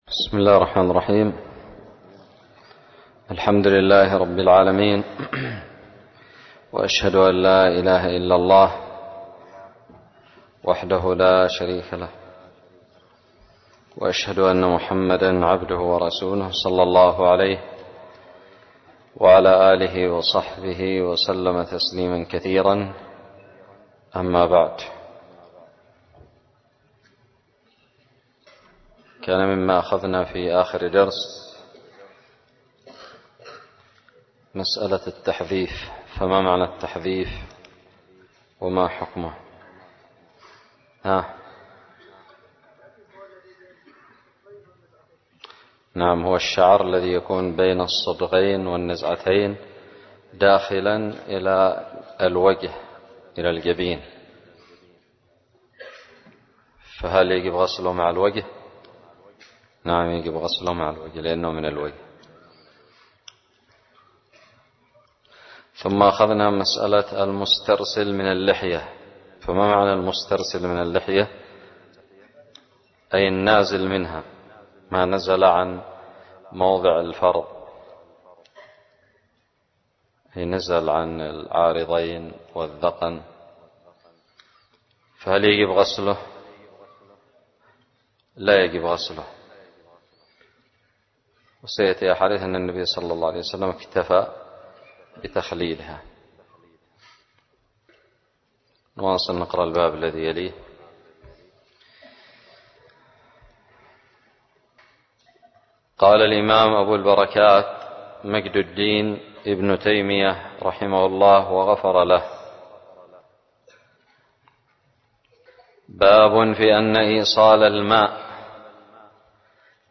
الدرس الحادي عشر بعد المائة من كتاب الطهارة من كتاب المنتقى للمجد ابن تيمية
ألقيت بدار الحديث السلفية للعلوم الشرعية بالضالع